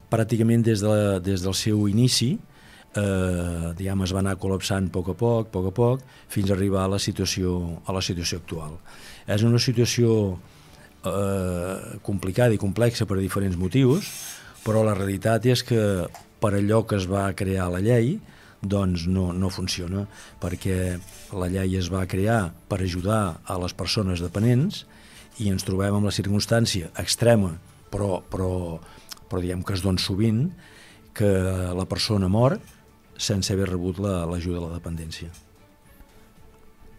En una entrevista concedida al programa Supermatí, el defensor del ciutadà de Palafrugell, Francesc Almagro, ha alertat sobre la situació “greu i injustificable” que viuen les comarques gironines en l’àmbit de les ajudes a la dependència. El temps d’espera per rebre una resolució pot arribar a superar l’any, molt lluny dels 90 dies que marca la llei.